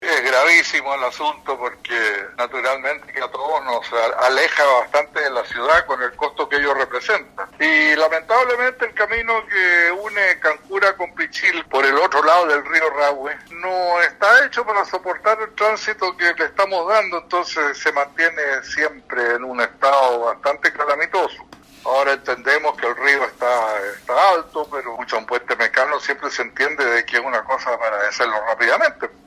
En conversación con Radio Sago, la alcaldesa de Puerto Octay, María Elena Ojeda se refirió al retraso que hubo producto del mal clima con la apertura del puente mecano en Cancura programado para este lunes 26 de noviembre, lo cual no sólo ha dificultado la conectividad, sino que además ha mantenido preocupada a la población, según los mismos afectados lo han mencionado.